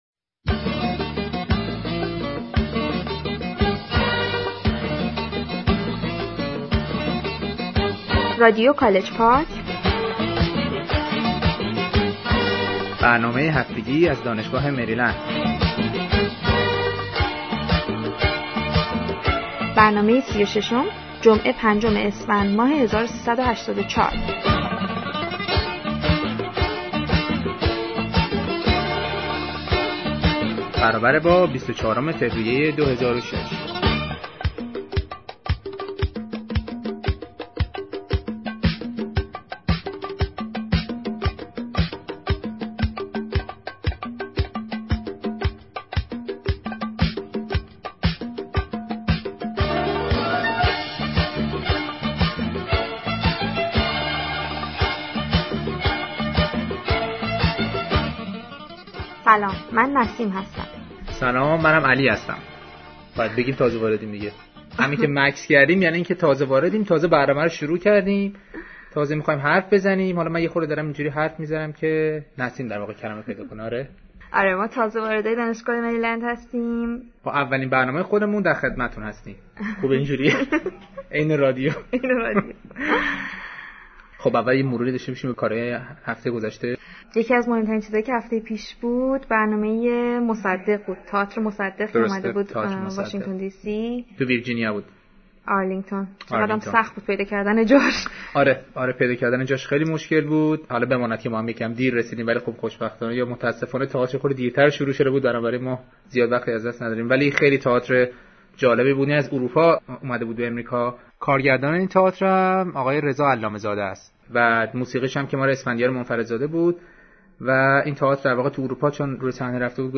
Persian folk music